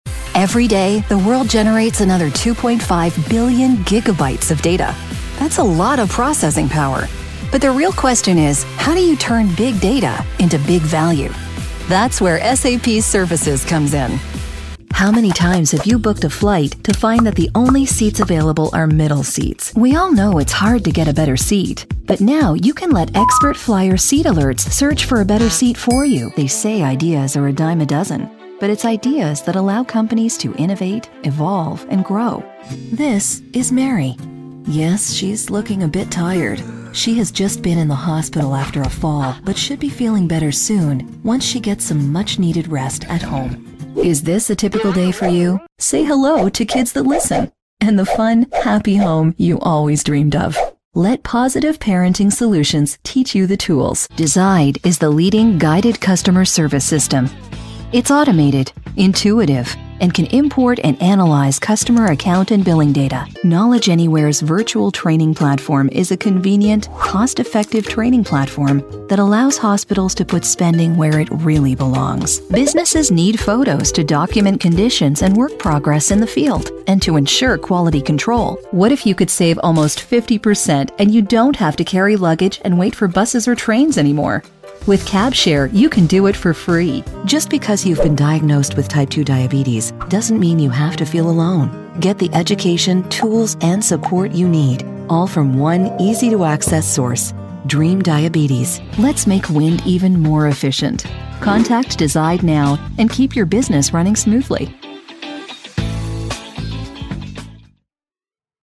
Her voice has been described as warm, friendly and comforting with a hint of gravitas, and coupled with her years of professional work in sound, will take your project to the next level.
Offering a warm, soothing alto shading from sexy to sarcastic to business with gravitas.
middle west
Sprechprobe: eLearning (Muttersprache):